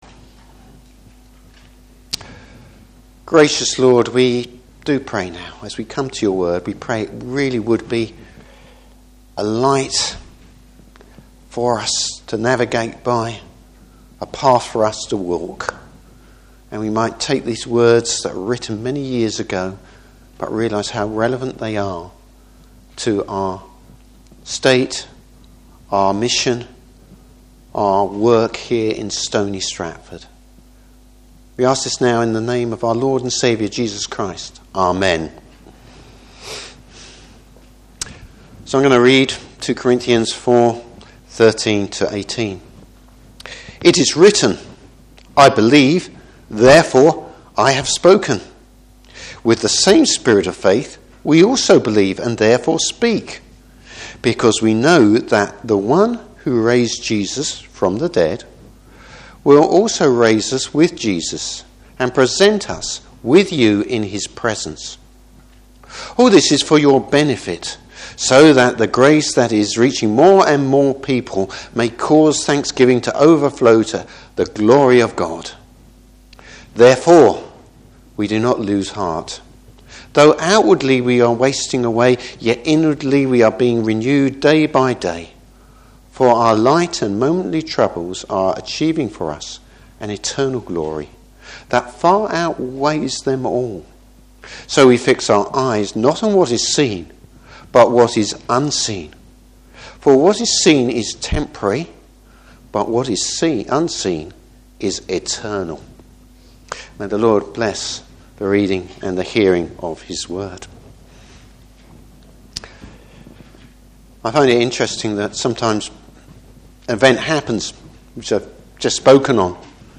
Service Type: Morning Service The past, present and future reality for the Christian.